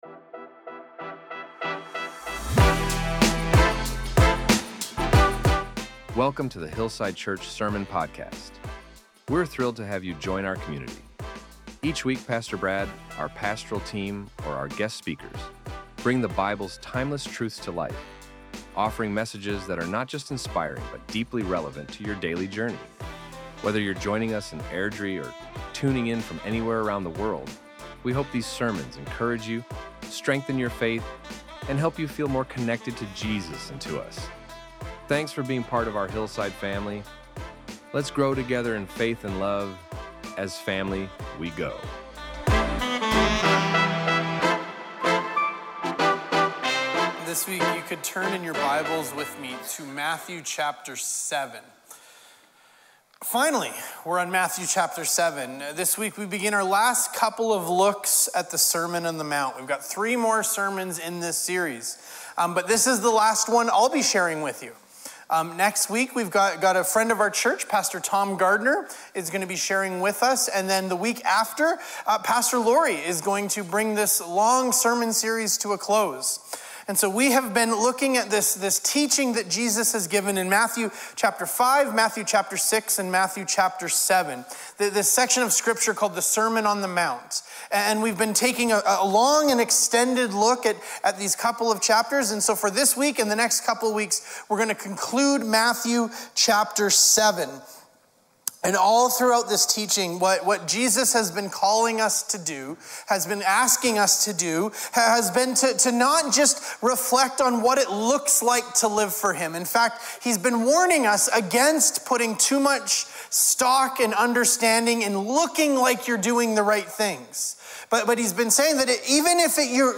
Our Sermons | HILLSIDE CHURCH